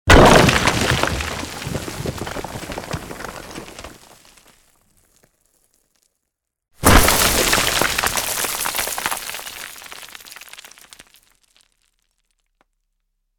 Tiếng Vỡ tan tần khối tường bê tông
Thể loại: Tiếng động
Description: Tiếng Vỡ tan tần khối tường bê tông là âm thanh đổ vỡ của một khối lớn gạch đá xi măng, là tiếng vỡ đồ đạc, âm thanh phá hủy công trình đã hết thời hạn sử dụng và xuống cấp, đây là âm thanh lao động nơi công trường xây dựng đầy nắng gió.
Tieng-vo-tan-tan-khoi-tuong-be-tong-www_tiengdong_com.mp3